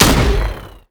AutoGun_3p_02.wav